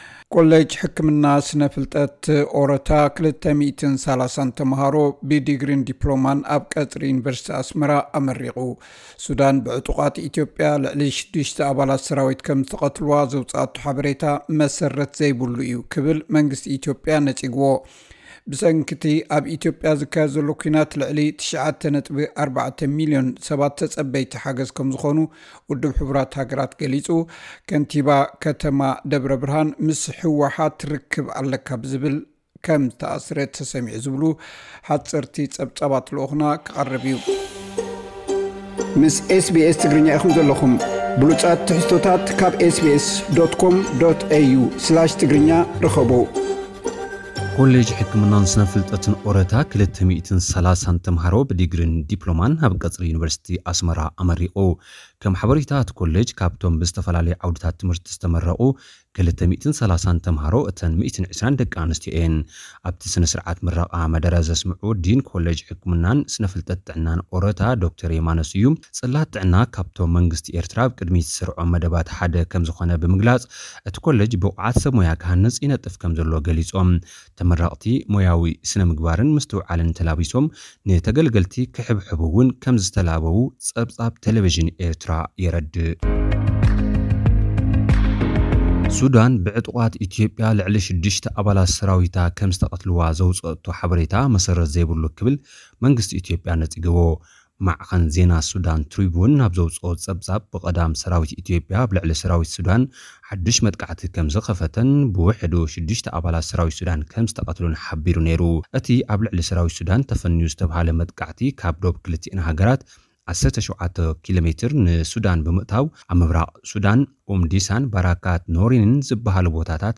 ብሰንኪ እቲ ኣብ ኢትዮጵያ ዝካየድ ዘሎ ኩናት ልዕሊ 9.4 ሚልዮን ሰባት ተጸበይቲ ሓገዝ ከም ዝኾኑ ውድብ ሕቡራት ሃገራት ገሊጹ። (ሓጸርቲ ጸብጻብ) | SBS Tigrinya